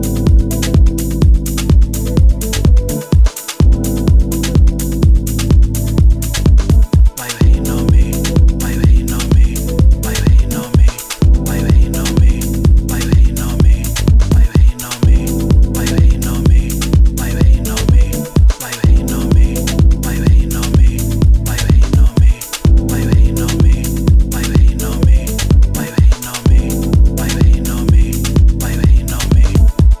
しっとりウォームでムーディーなフィーリングを基調とし、ジャジーな音色も程よくちりばめながら